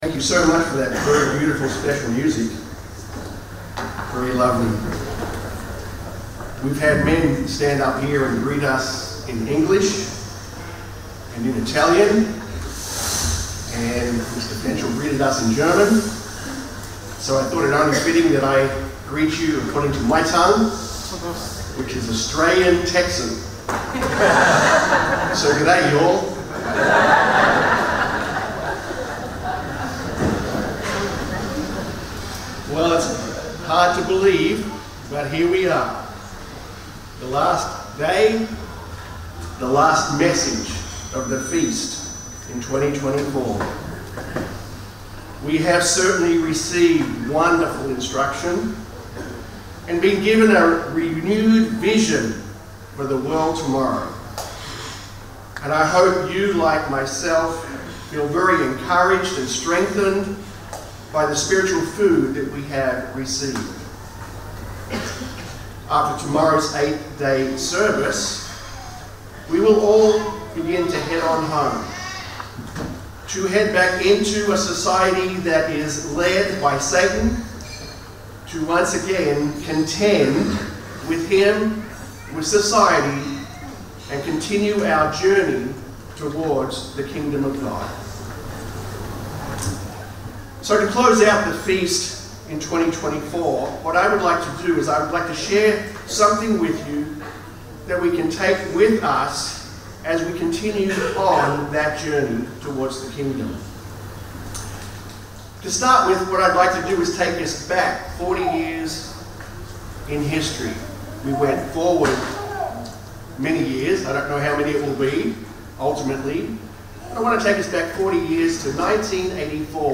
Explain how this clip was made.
FoT 2024 Marina di Grosseto (Italy): 7th day